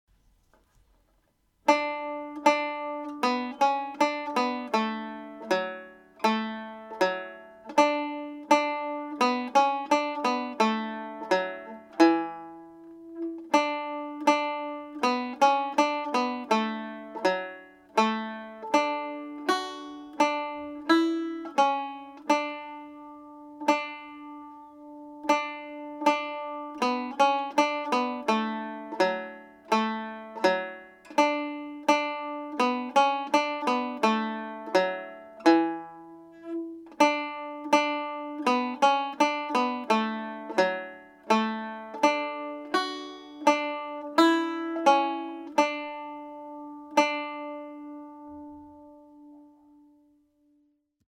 first part played slowly
Kerry-Polka_1st-part.mp3